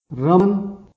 Výslovnost bangladéšských jmen
Naši kolegové z Bangladéše nám nahráli správnou výslovnost vybraných jmen.